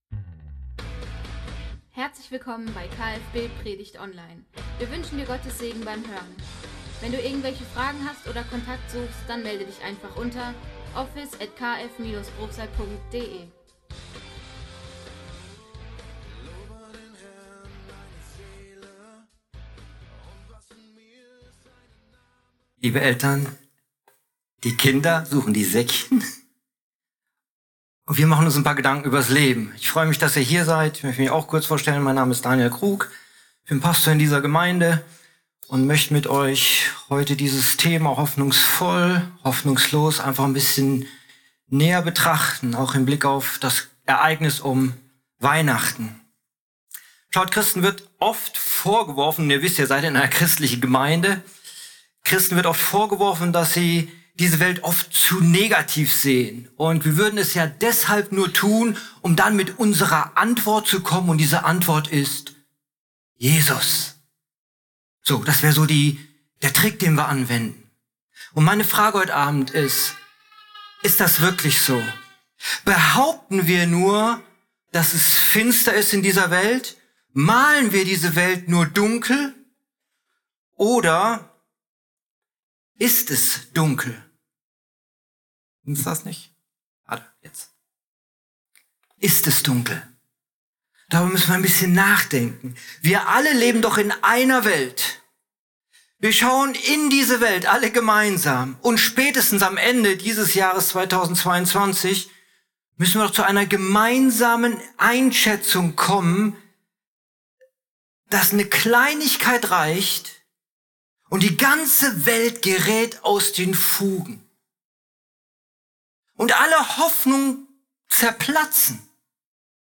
Heiligabendgottesdienst